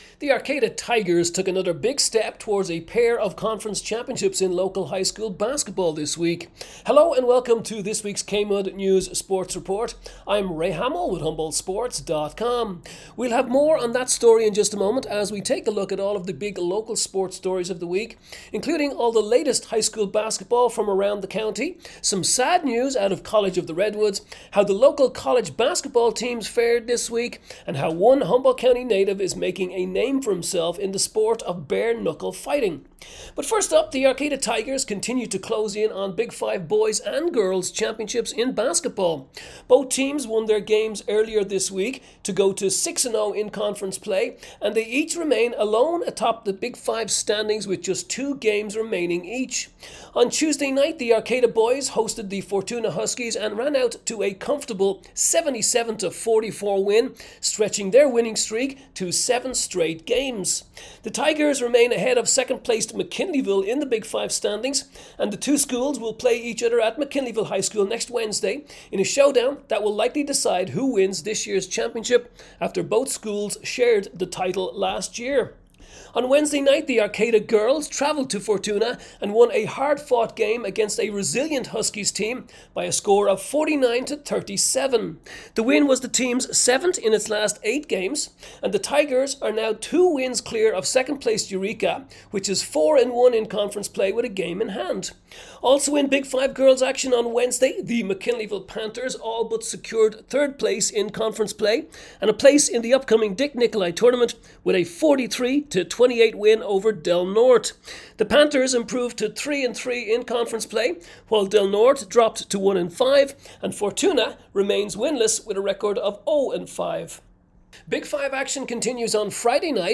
JAN 31 KMUD News sports report